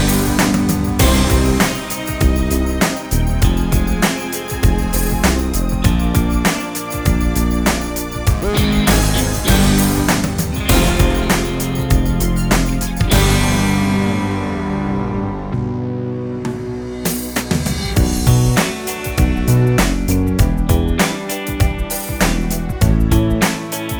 no Backing Vocals Country (Female) 3:32 Buy £1.50